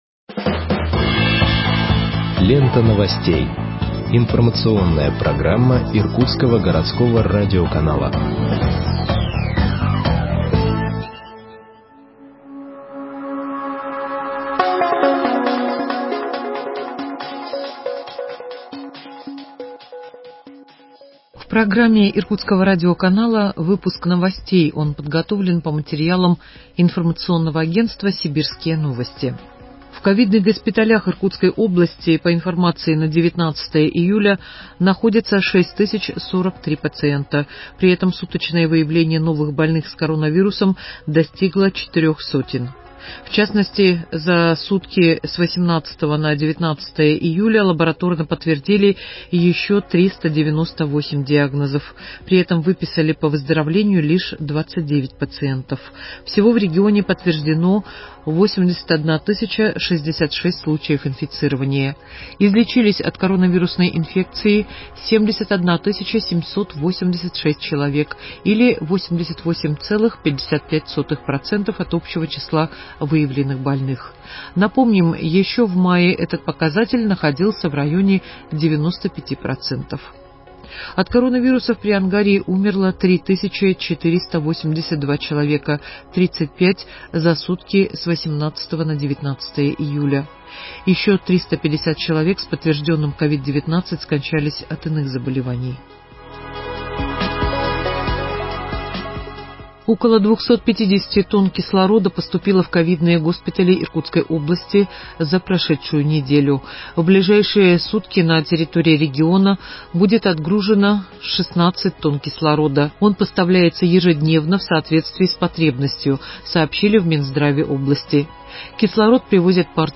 Выпуск новостей в подкастах газеты Иркутск от 20.07.2021 № 2